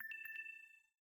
LowBatteryInCall.ogg